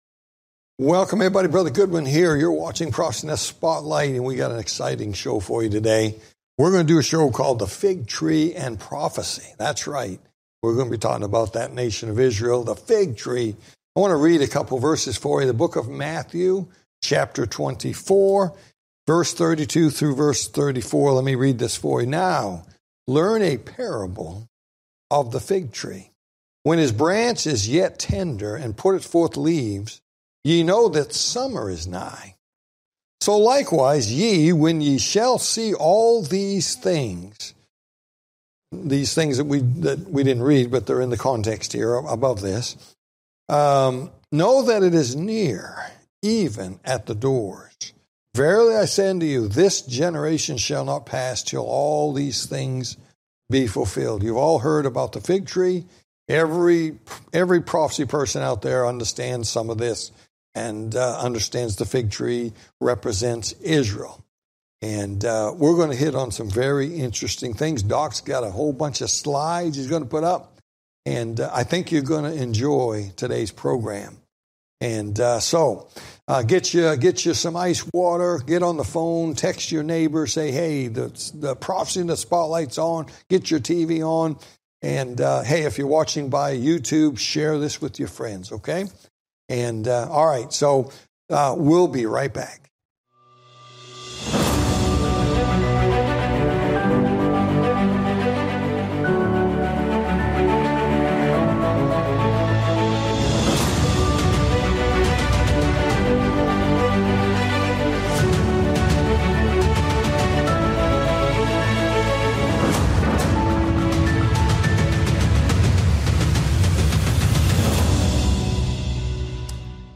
Talk Show Episode, Audio Podcast, Prophecy In The Spotlight and The Fig Tree And Prophecy, And The Second Coming Of Moses Part 1 on , show guests , about The Fig Tree And Prophecy,The Second Coming Of Moses, categorized as History,News,Politics & Government,Religion,Society and Culture,Theory & Conspiracy